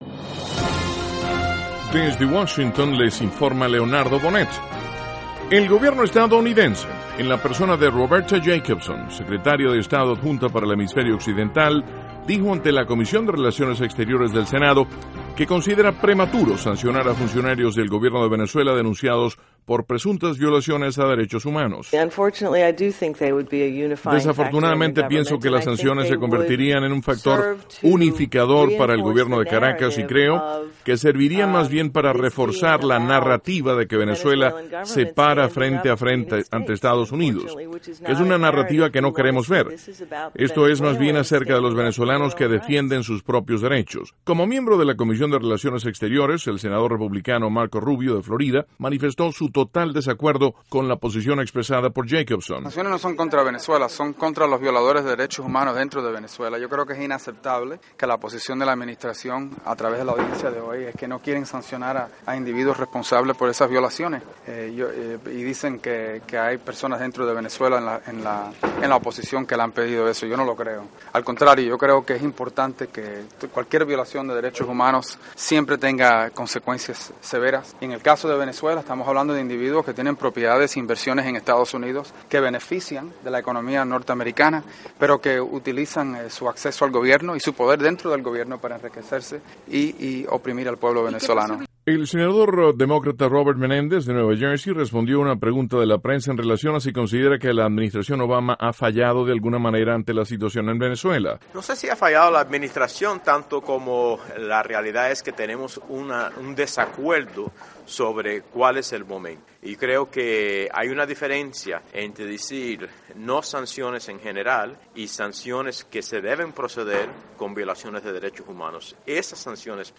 Duración: 3:27 Contenido: 1.- Comisión de Relaciones Exteriores del Senado analiza situación política de Venezuela. (Sonidos Jacobson, Rubio y Menéndez. 2.- El secretario de Estado, John Kerry, recibe a presidente de la Coalición de Oposición en Siria. (Sonido Kerry)